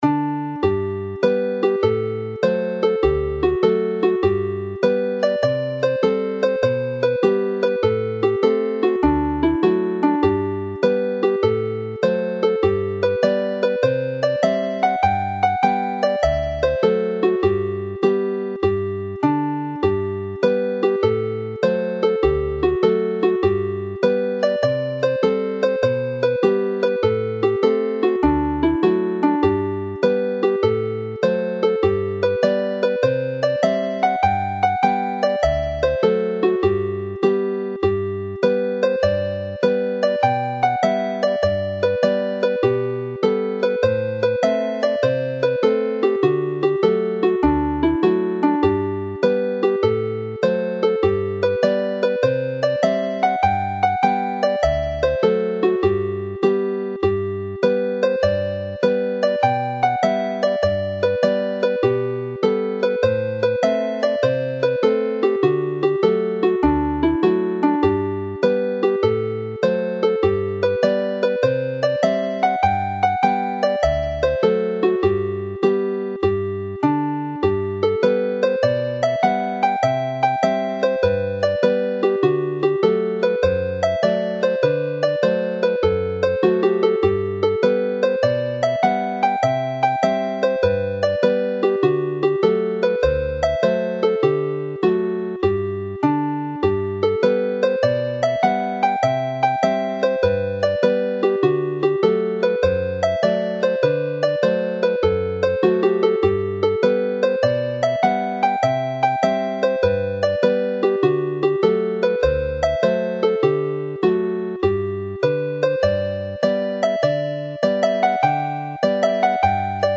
This set of hornpipes has Pibddawns Aberhonddu  (Brecon hornpipe) and the Merthyr hornpipe, separated by the Brecon Beacons, with Galwyni Dros y Bar (Gallons over the bar) starting the set.